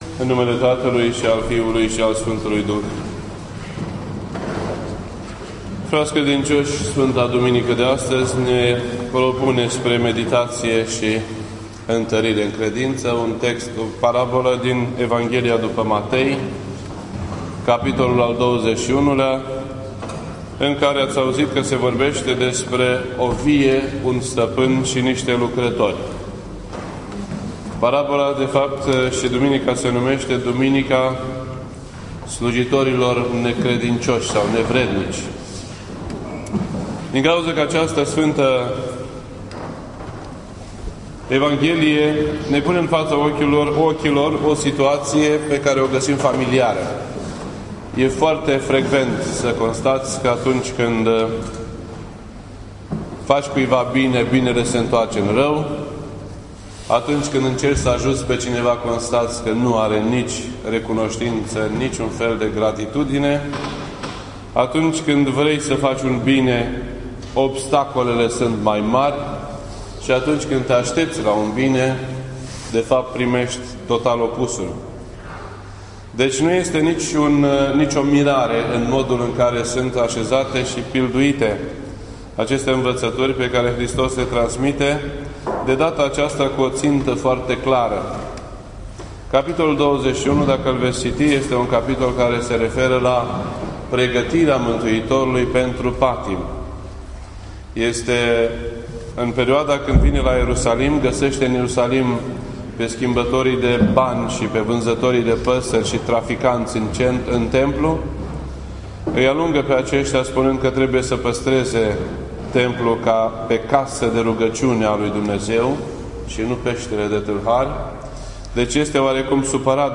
This entry was posted on Monday, August 31st, 2015 at 6:09 PM and is filed under Predici ortodoxe in format audio.